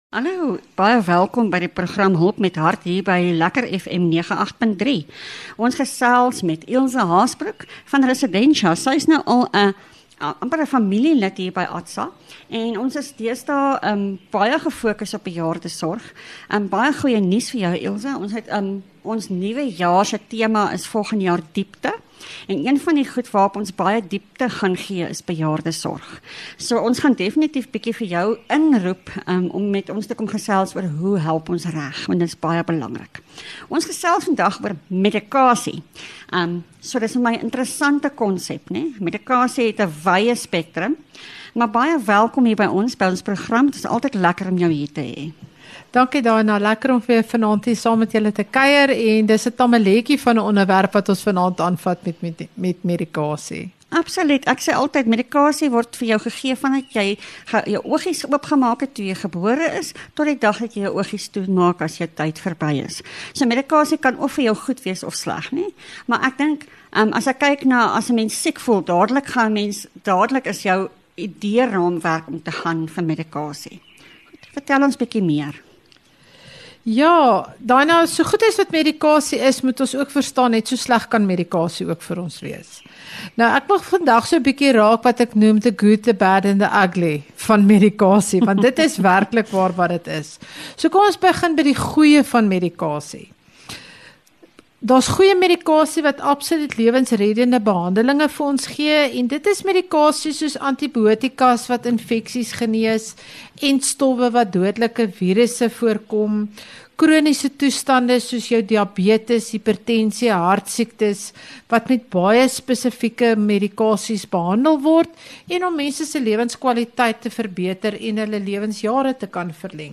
LEKKER FM | Onderhoude 20 Nov Hulp met Hart